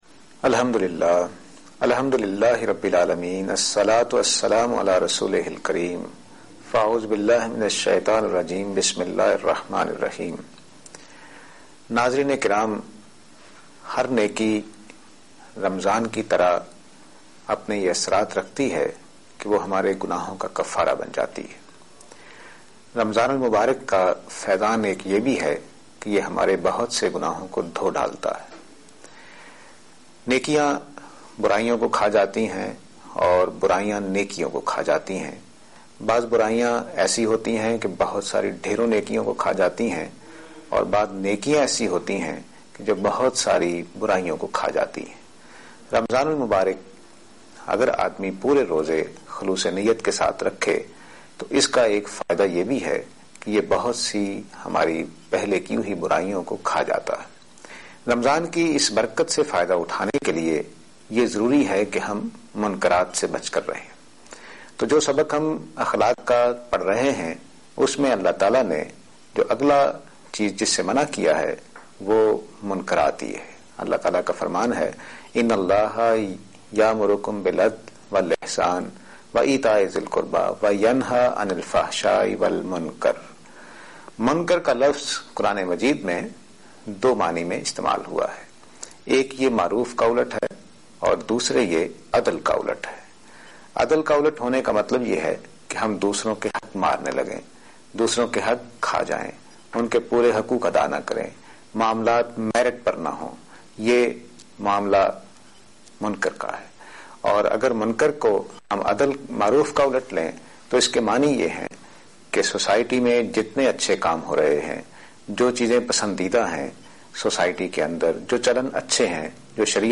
Program Tazkiya-e-Ikhlaq on Aaj Tv.